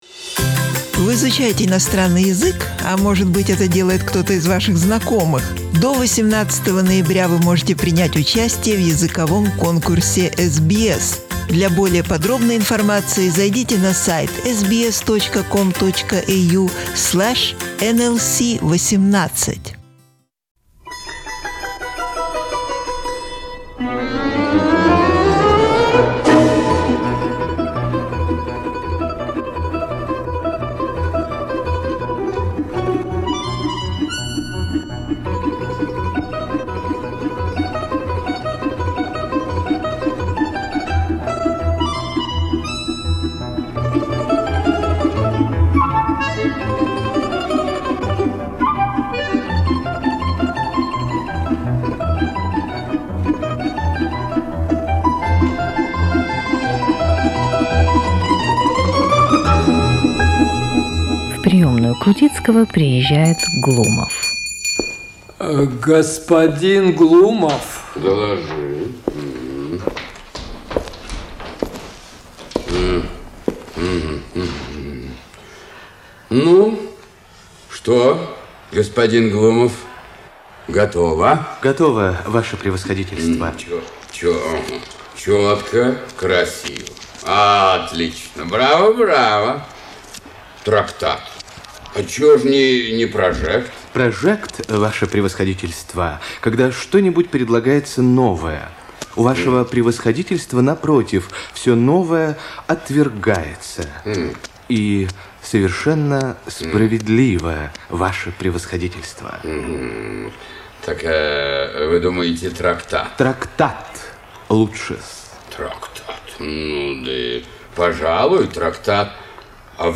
His energy attracted to the studio many amateur and professional actors of different ages from many different cities of the former USSR.